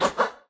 Media:RA_Dragon_Chicken_atk_clean_005.wav 攻击音效 atk 局内攻击音效
RA_Dragon_Chicken_atk_clean_003.wav